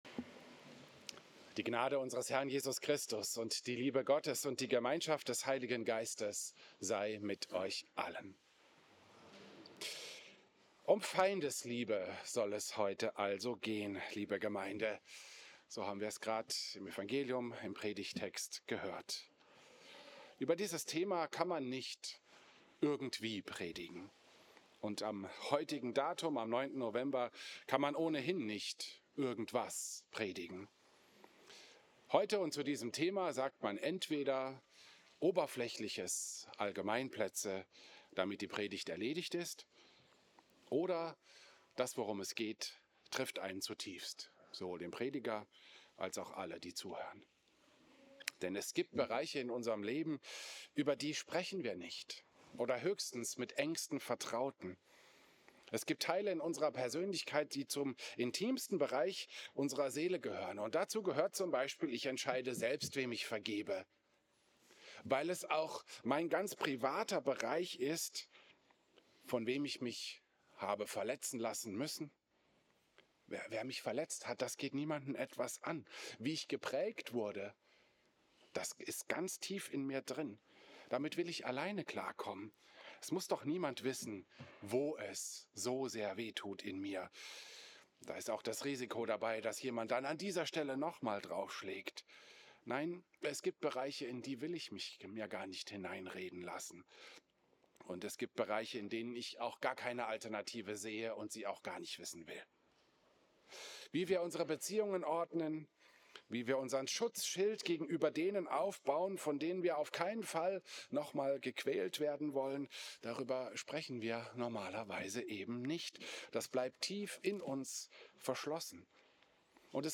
Klosterkirche Volkenroda, 9. November 2025